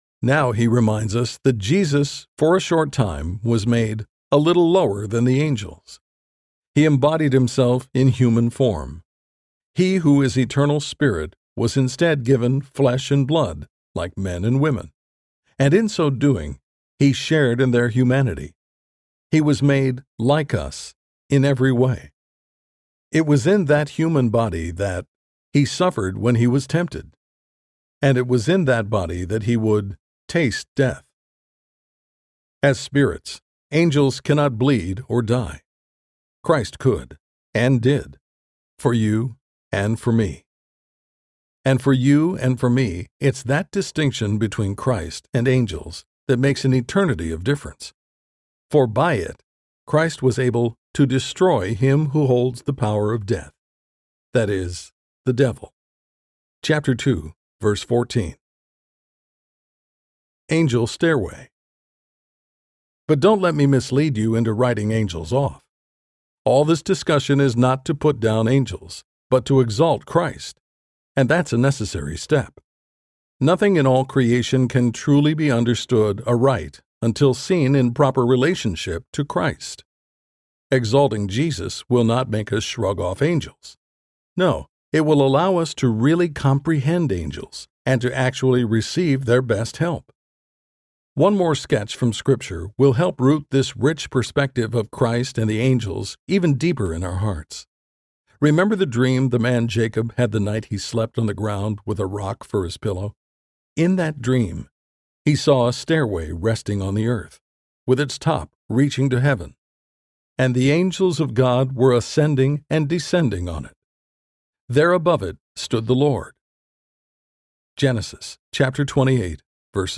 Angels Audiobook
Narrator
7.32 Hrs. – Unabridged